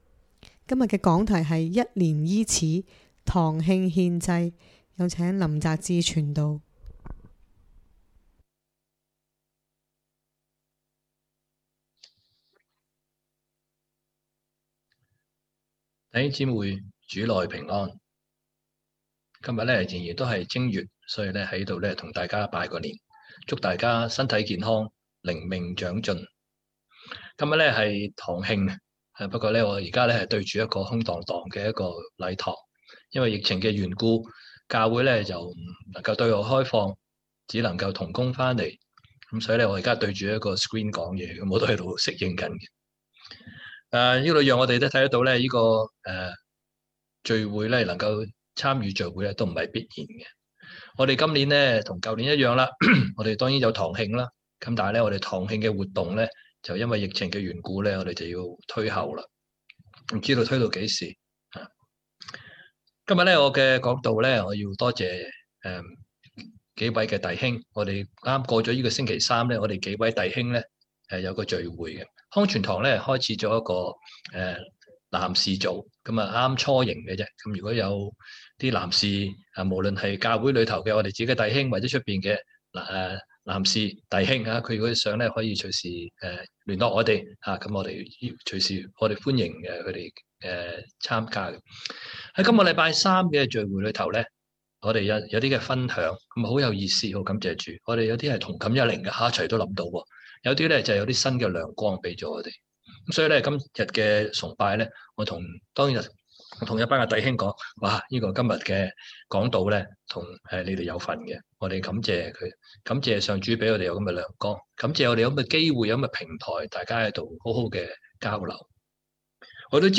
2022 年 2 月 20 日講道